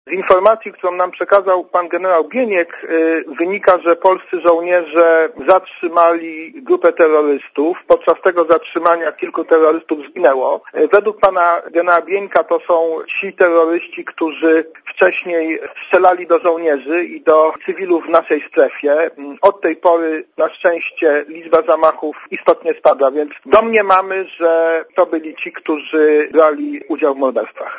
Posłuchaj komentarza wiceszefa MON